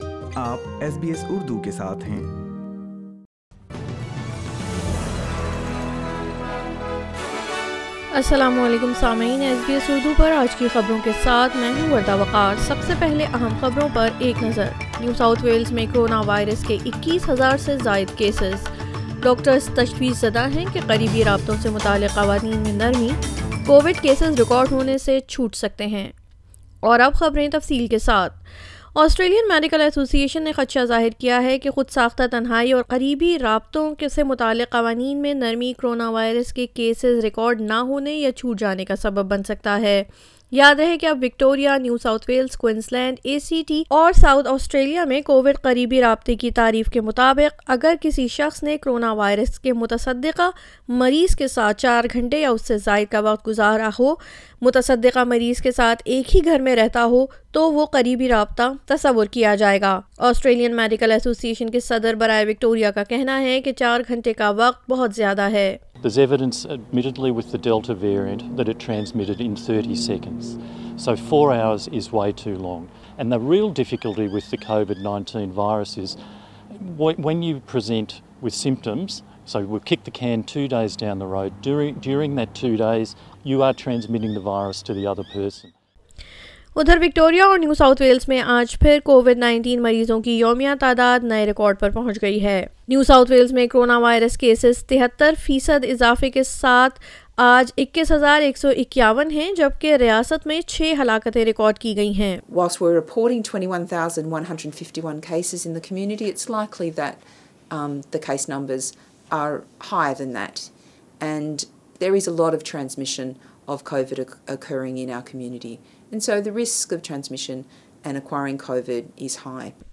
SBS Urdu News 31 December 2021